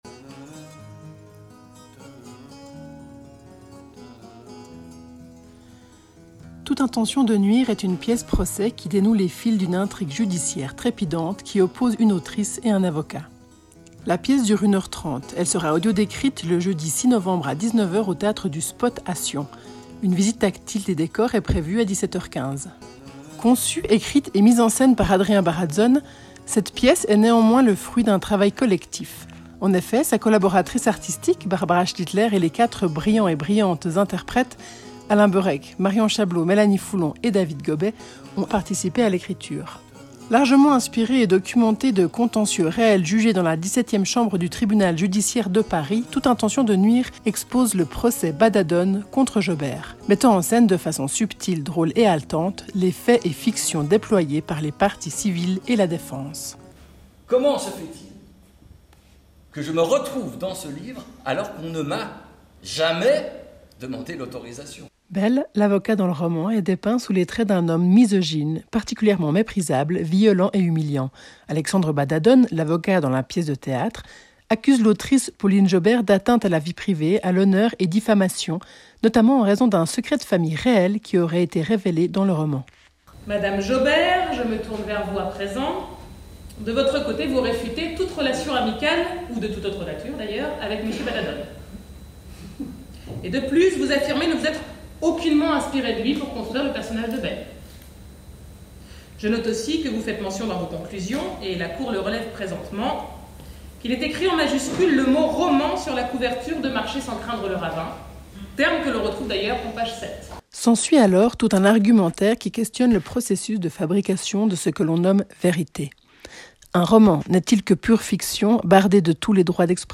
Théâtre
Audiodescription
Bande annonce